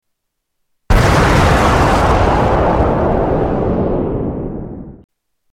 Huge blast explosion